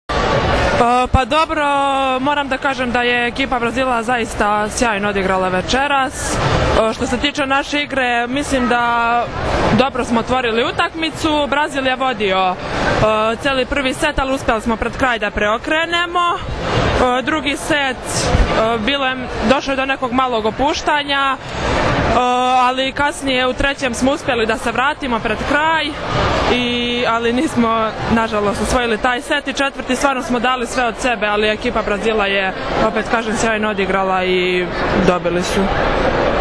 IZJAVA TIJANE BOŠKOVIĆ